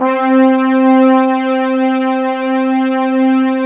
ORCHEST.C4-R.wav